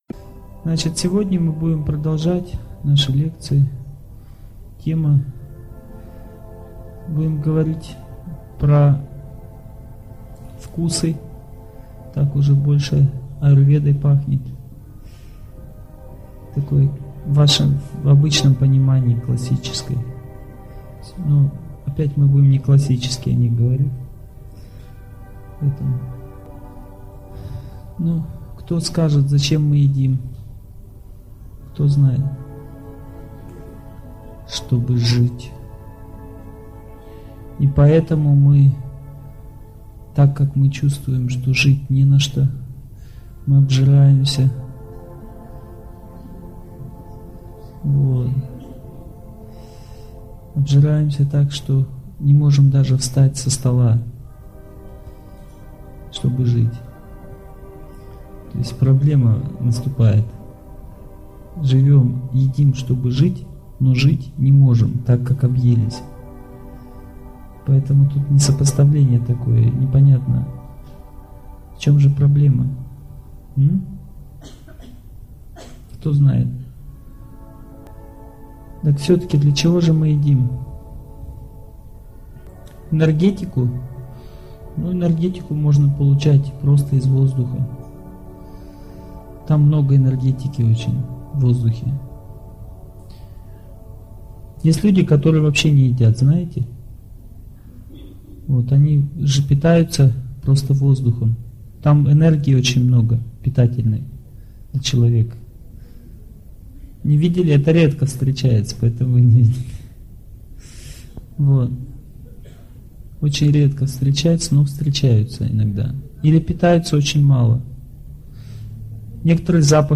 Аудиокнига Как быть здоровым и счастливым | Библиотека аудиокниг